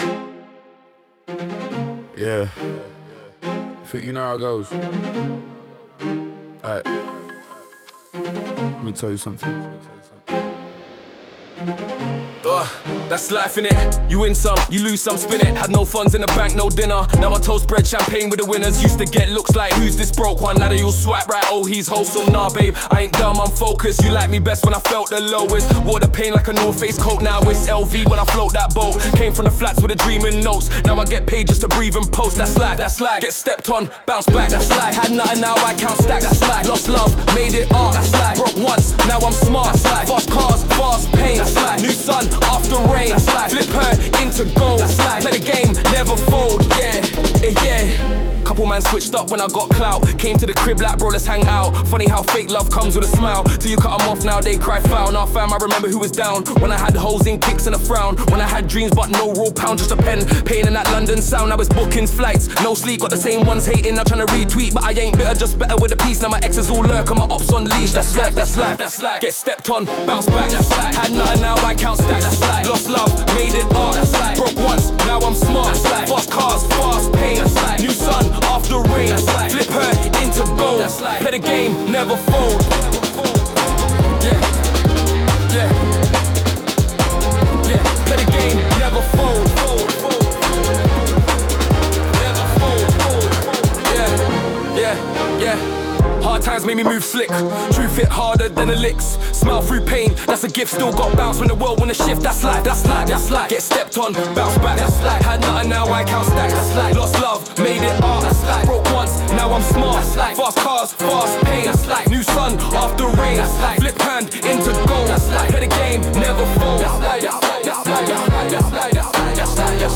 Genre: UK Rap Mood: Success